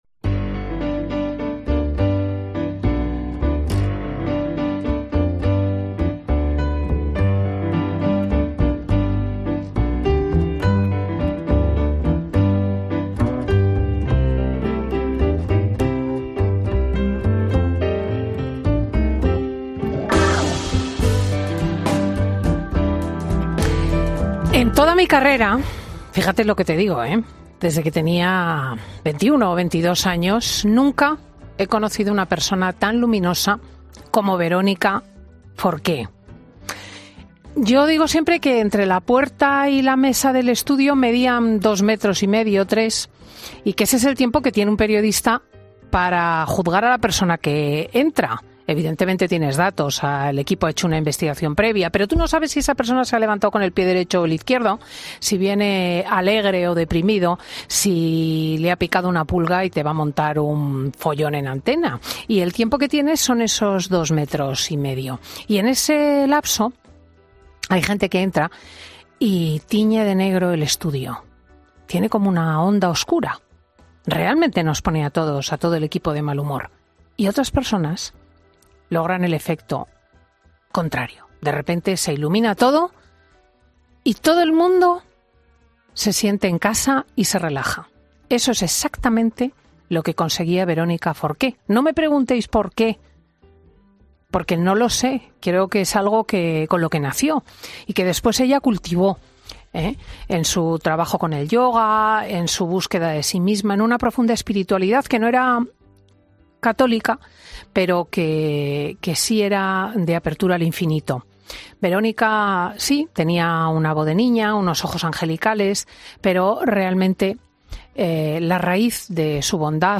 Marian Rojas, psiquiatra y autora de 'Encuentra tu persona vitamina', cuenta en Fin de Semana con Cristina cómo lograr que la desesperación no lleve a un final fatal